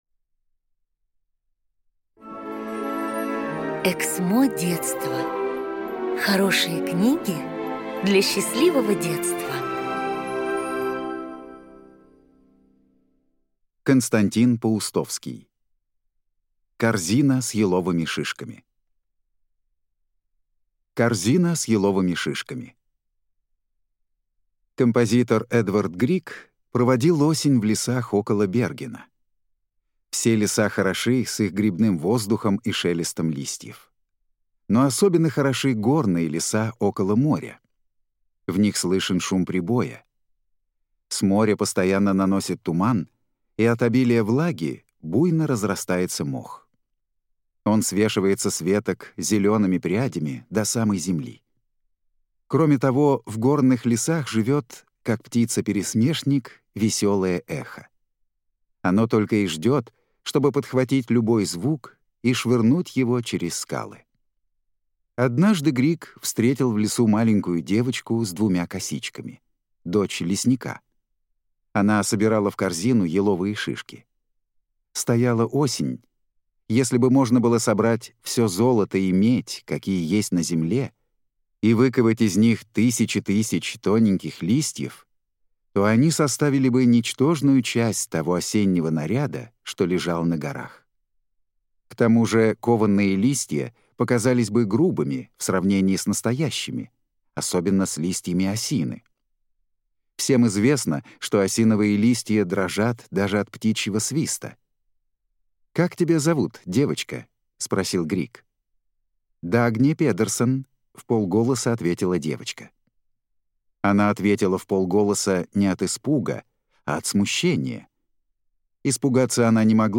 Аудиокнига Корзина с еловыми шишками | Библиотека аудиокниг